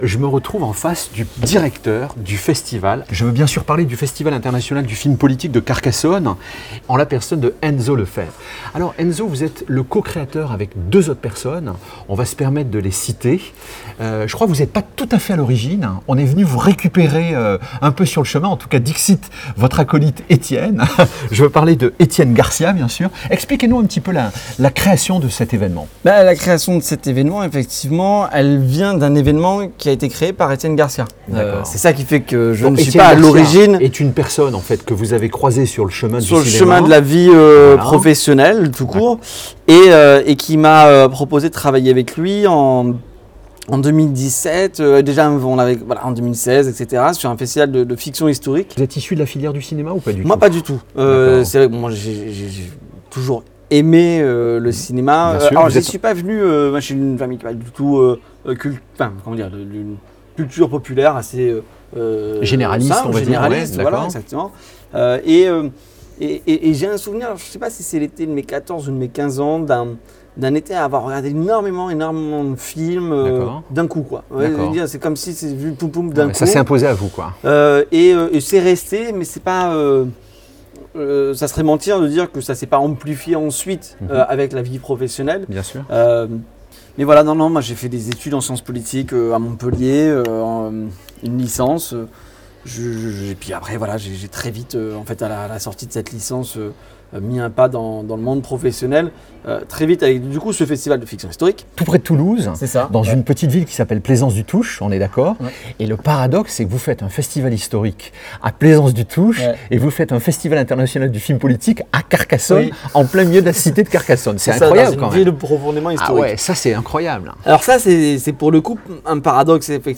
%%Les podcasts, interviews, critiques, chroniques de la RADIO DU CINEMA%%